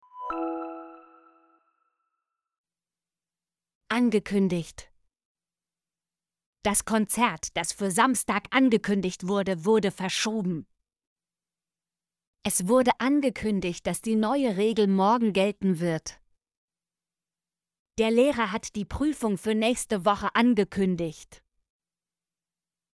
angekündigt - Example Sentences & Pronunciation, German Frequency List